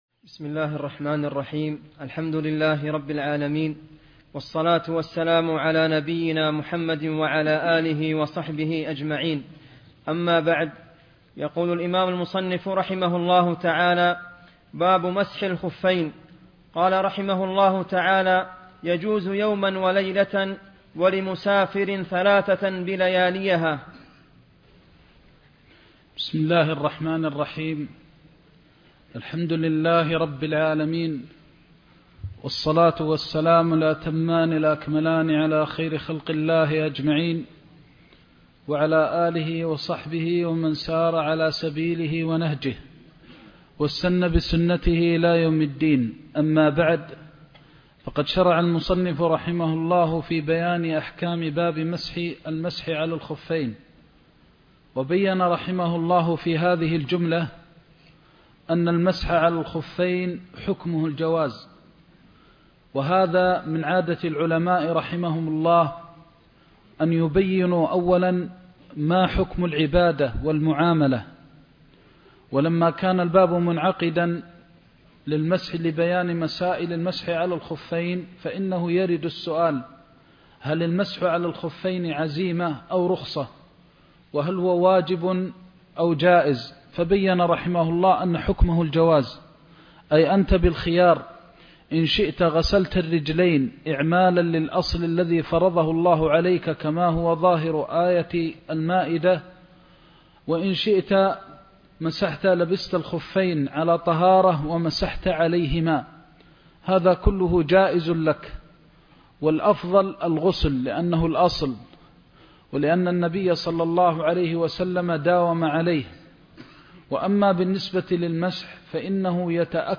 زاد المستقنع كتاب الطهارة (16) درس مكة